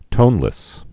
(tōnlĭs)